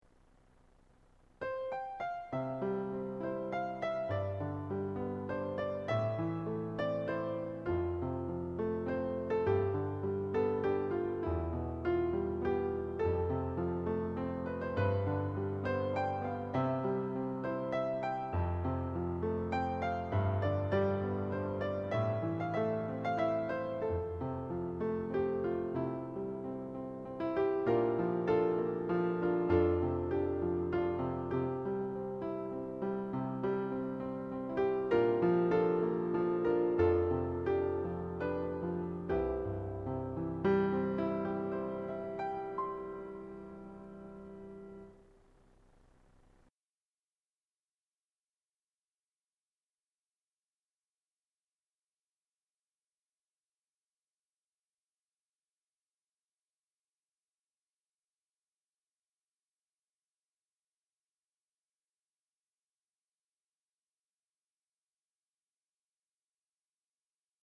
Can anyone help me eliminate PC noise?
I am getting loads of PC noise on the sound I record.
This improves the quality slightly as it cuts out the noise during the silence but I can still hear noise over the notes that are being played.